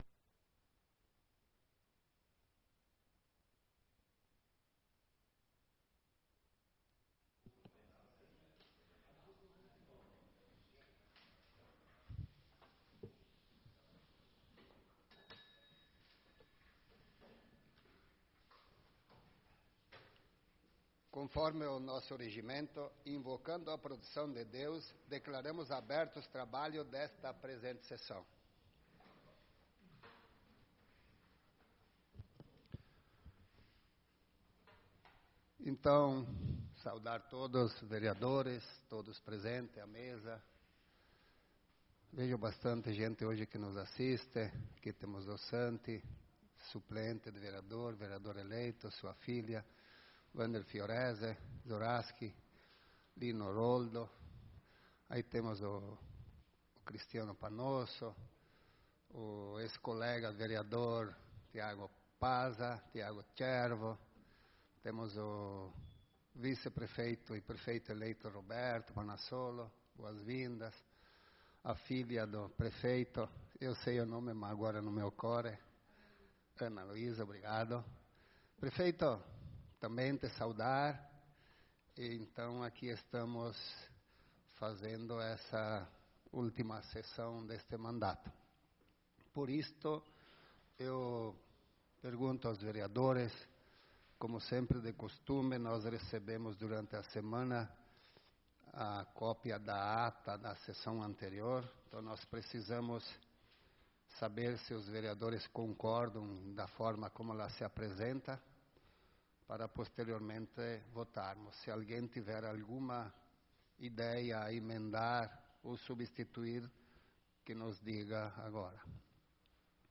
Sessão Ordinária do dia 17/12/2024
Tribuna Livre com o prefeito Douglas Fávero Pasuch